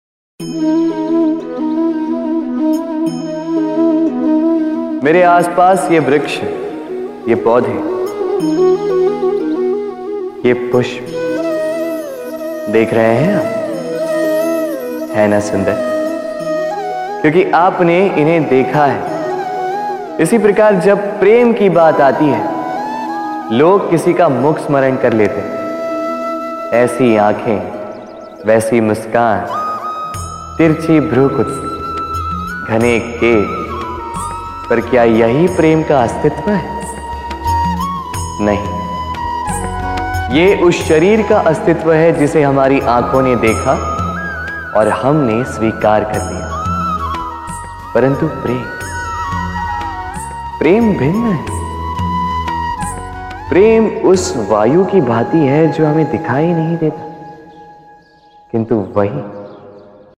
Categories Krishna Vani Ringtones